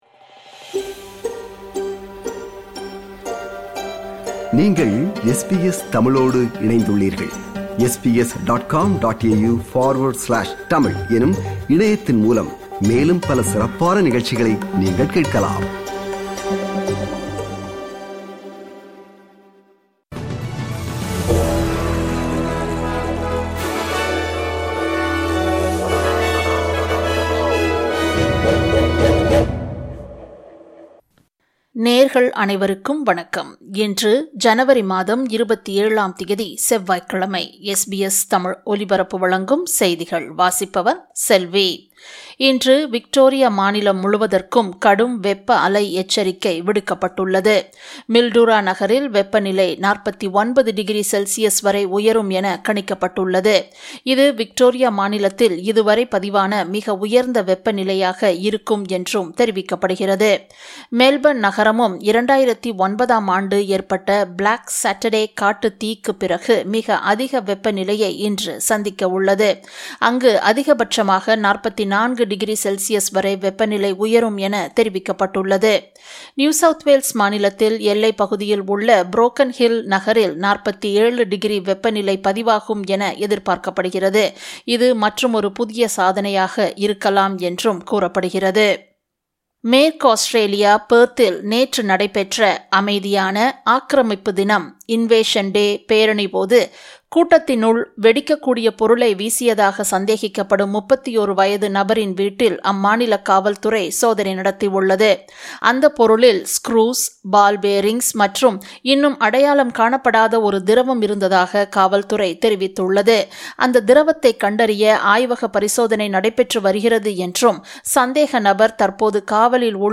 SBS தமிழ் ஒலிபரப்பின் இன்றைய (செவ்வாய்க்கிழமை 27/01/2026) செய்திகள்.